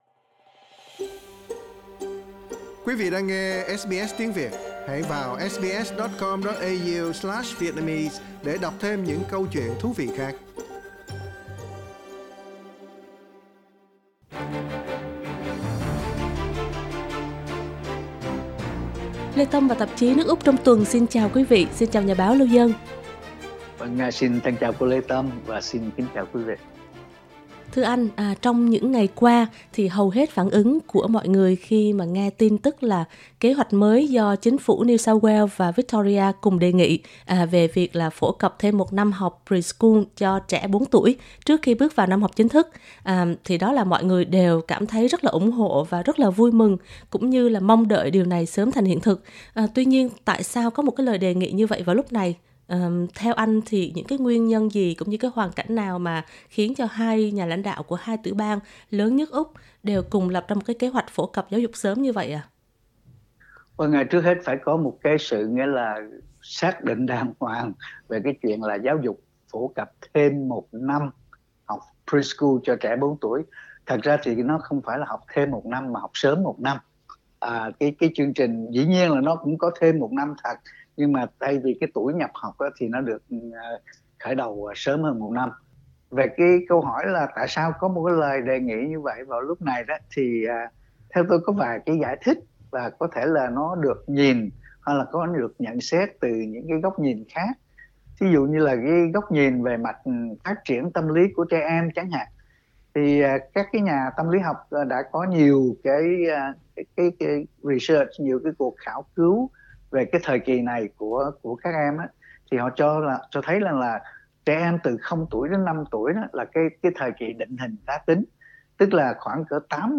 bài bình luận